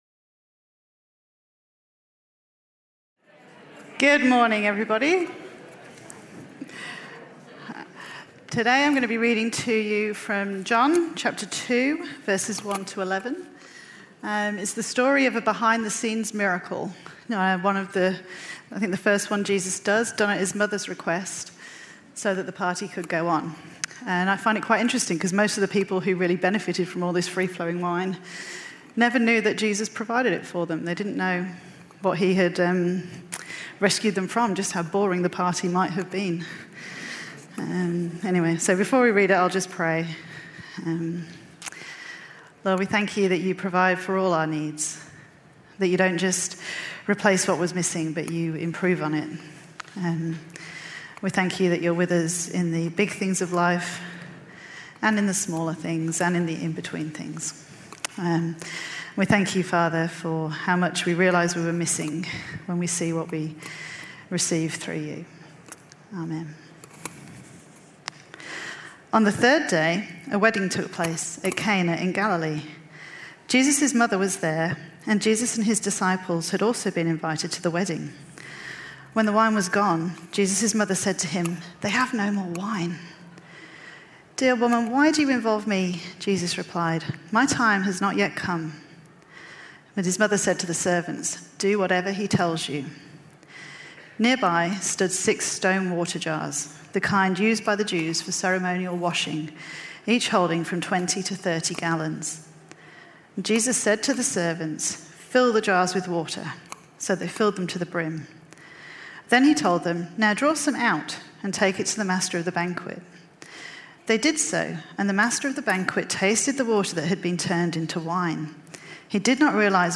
Talk-TheLifeOfTheParty.mp3